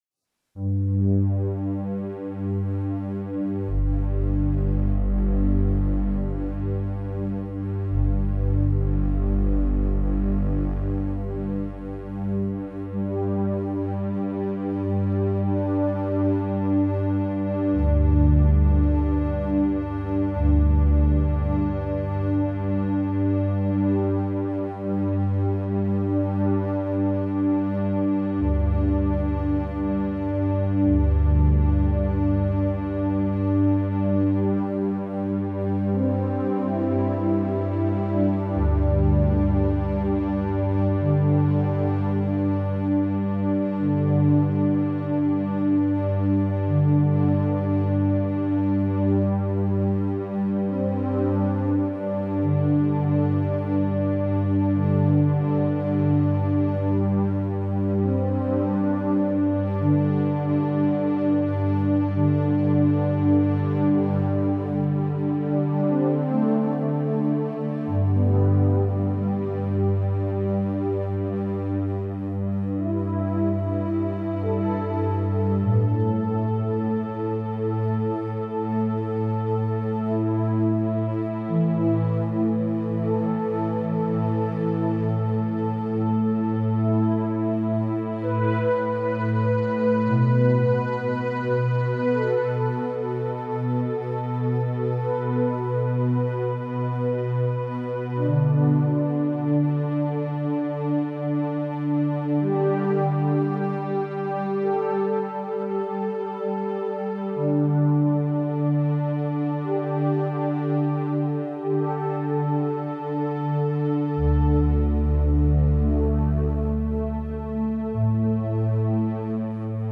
乐曲充满宇宙虚空能量的旋律，主理任脉、督脉的顺畅。